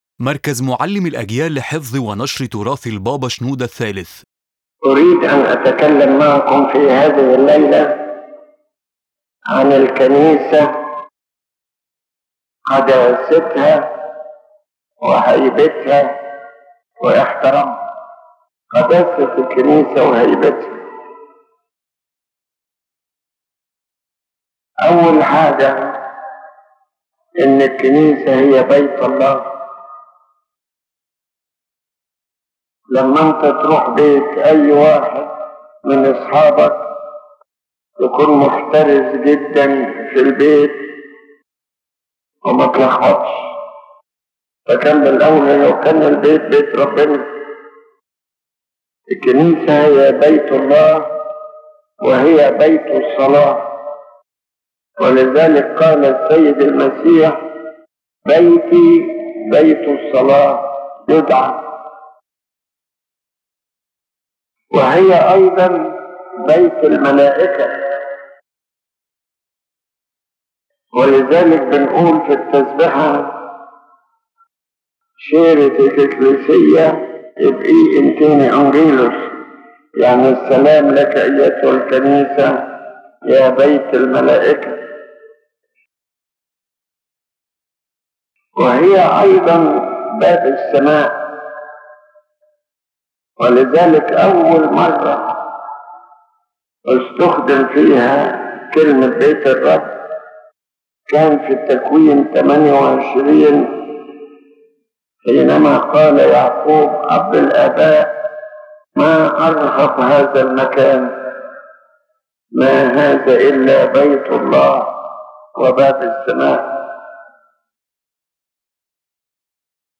His Holiness Pope Shenouda speaks about the sanctity of the church and the necessity of respecting it, explaining that the church is the house of God, the house of prayer, and a place where angels and people meet together in one prayer and one teaching.